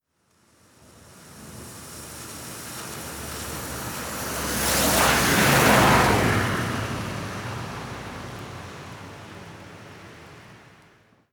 Coche pasando sobre charcos 4
charco
coche
Sonidos: Agua
Sonidos: Transportes